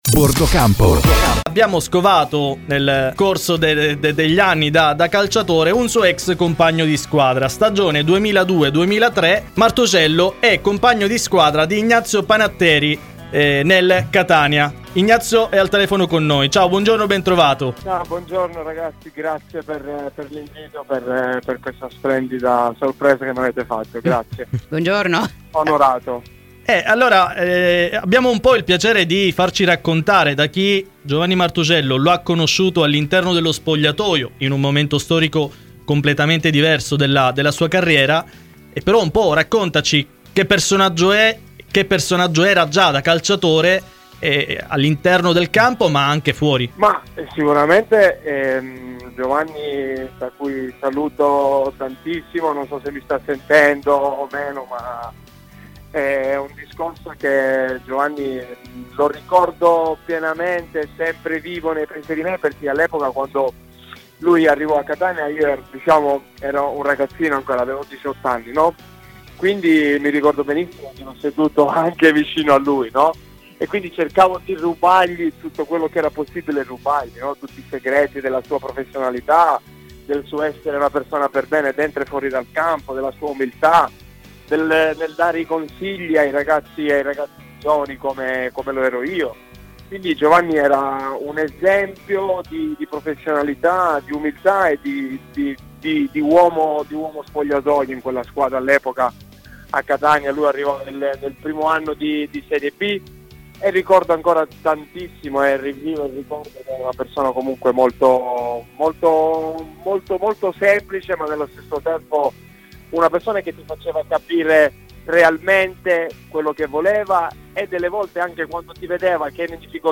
ha parlato ai microfoni di ‘Bordocampo’ in onda su Radio Bianconera